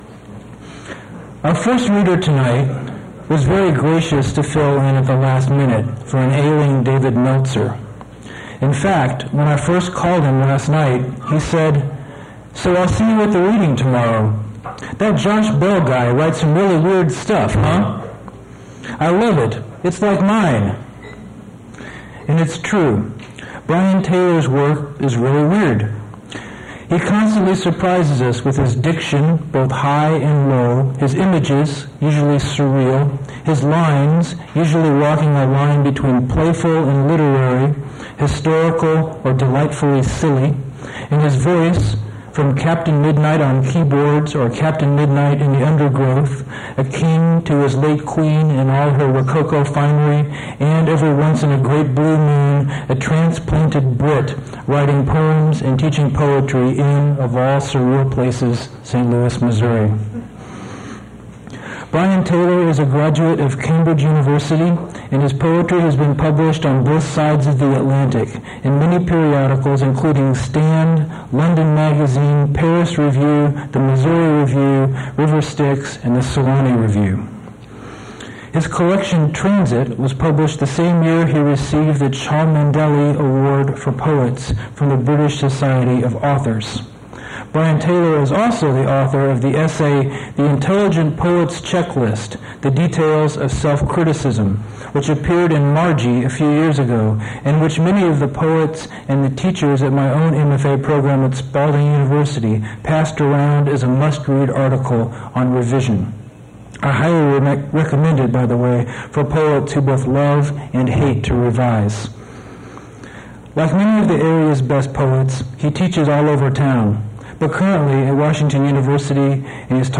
poetry reading
original audio cassette
Audio quality is not the best - a little loud/muffled (especially introduction);